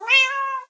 meow3.ogg